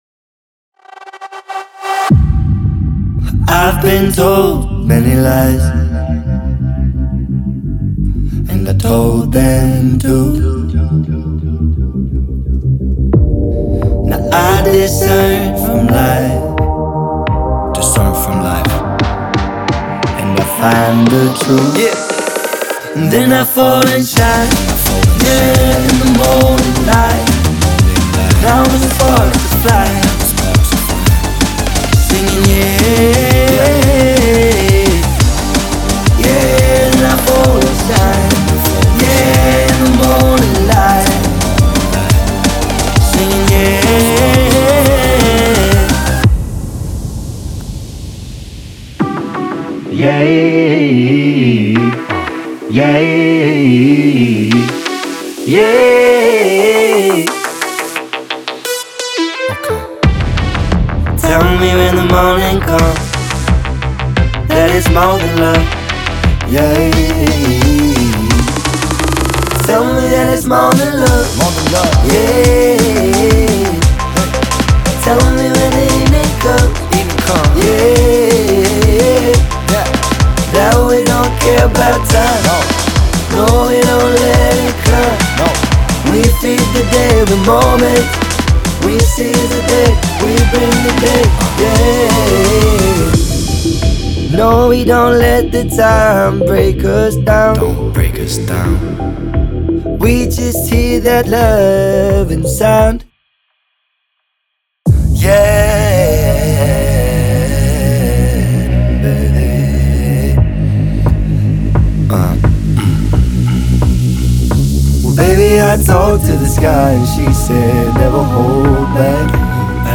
Genre:Drum and Bass
メインステージのエネルギーに最適な存在感あるボイスが特徴です。
すべての素材は174BPMに固定されており、既存のDnBプロジェクトへそのまま簡単に組み込めます。
すべてのボーカルは最大限の創作自由度を確保するためドライ音源で提供され、相互に組み合わせて使えるよう最適化されています。
デモサウンドはコチラ↓